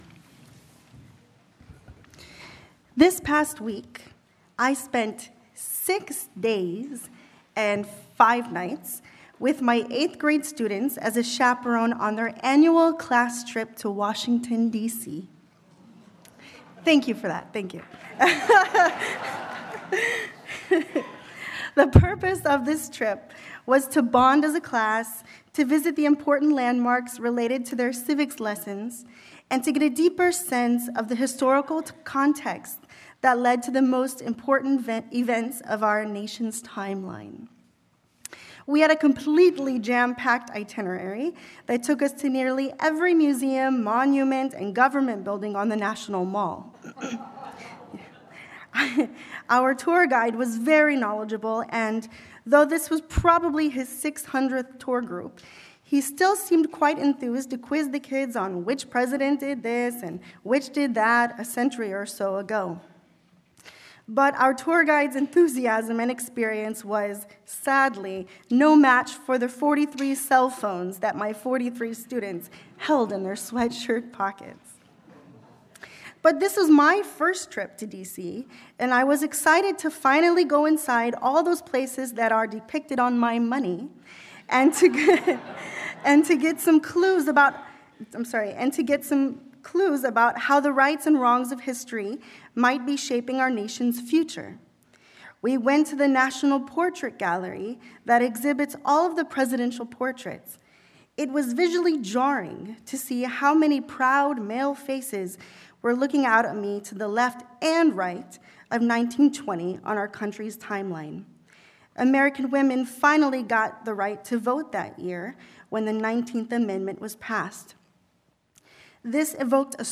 Sermon-A-UU-Theology-for-the-21st-Century.mp3